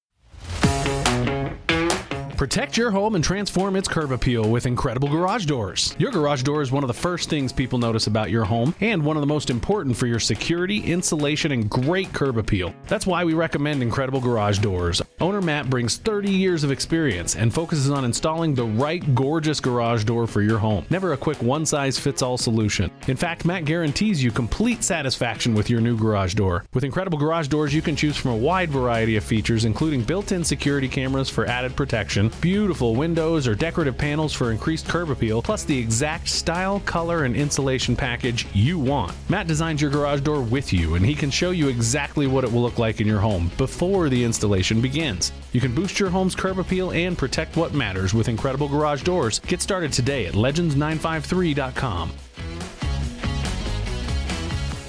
igd-mar-spot-mixdown-1.mp3